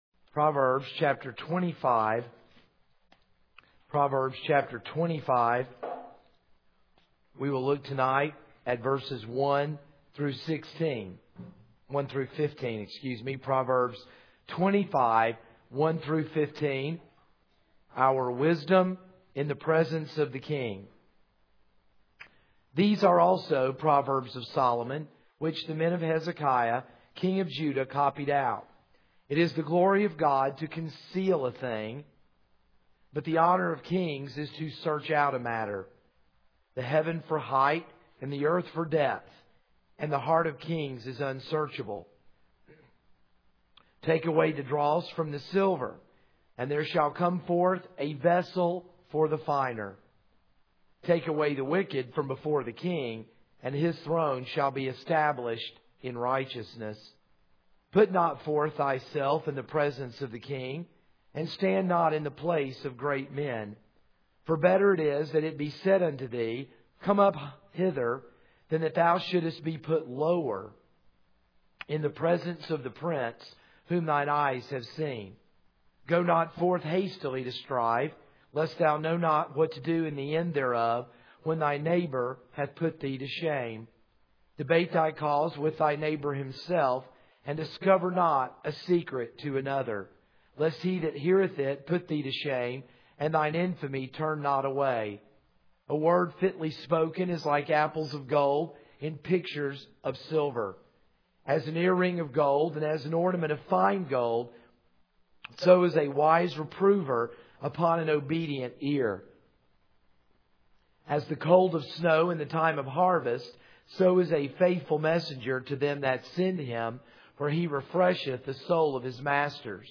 This is a sermon on Proverbs 25:1-15.